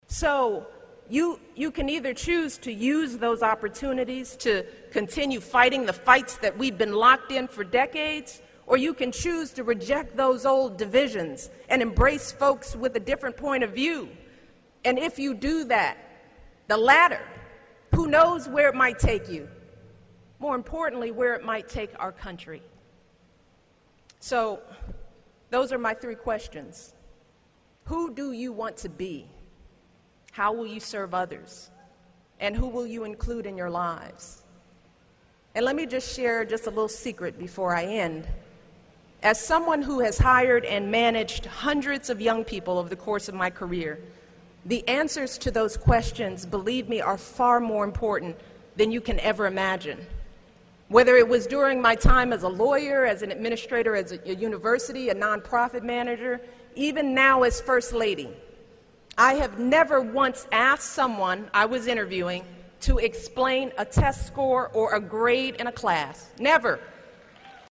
公众人物毕业演讲第275期:米歇尔2013东肯塔基大学16 听力文件下载—在线英语听力室